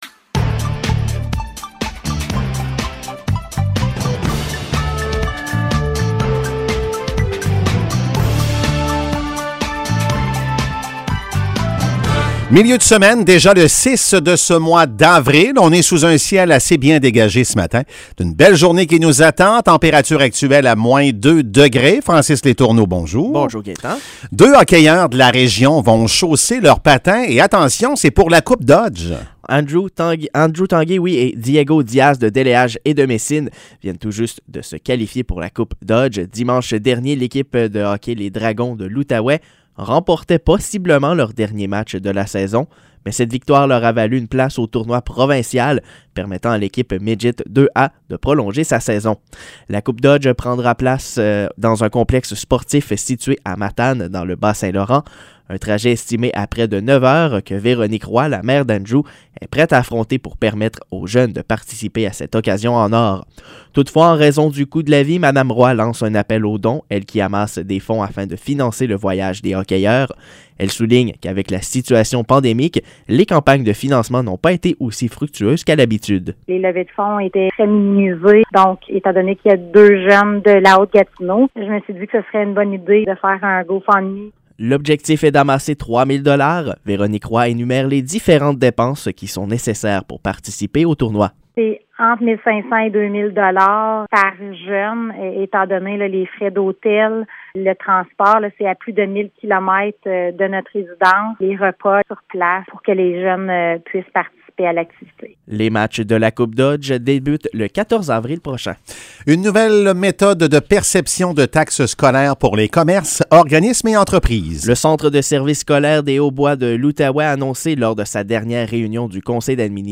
Nouvelles locales - 6 avril 2022 - 7 h